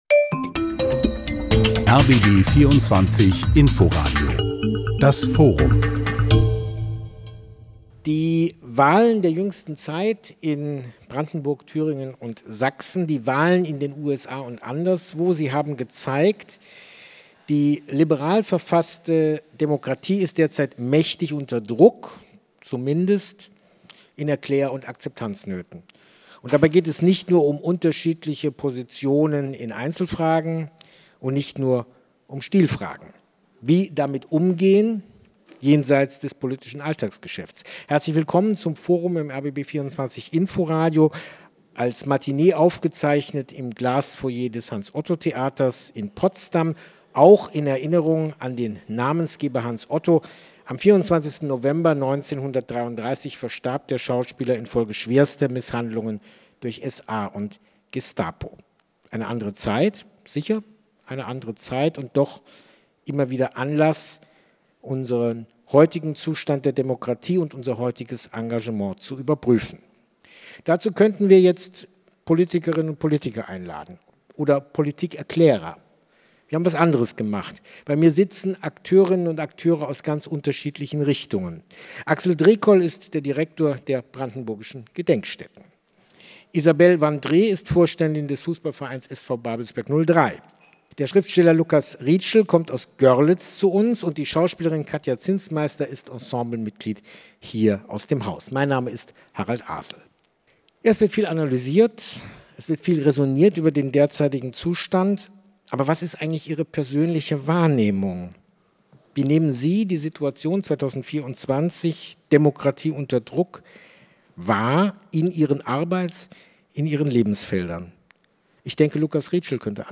Und so fragen wir bei diesem Podiumsgespräch: Was heißt es, unsere Demokratie zu verteidigen?